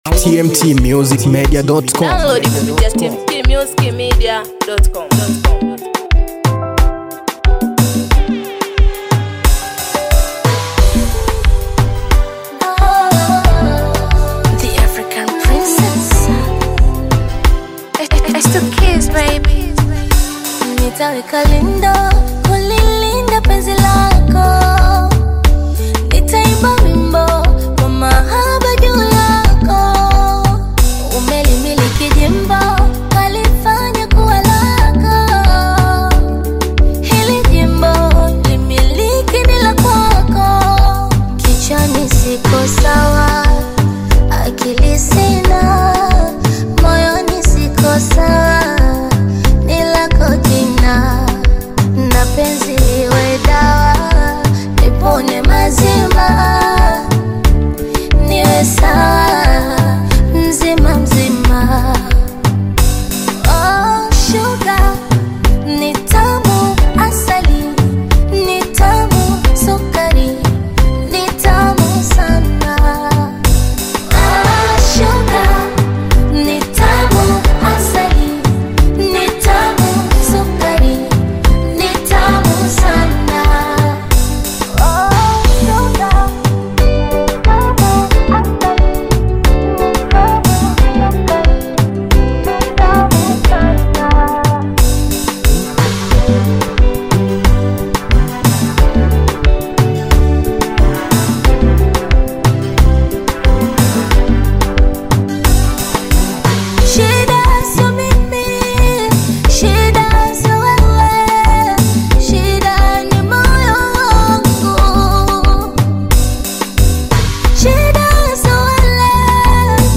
BONGO FLAVOUR
Afro beat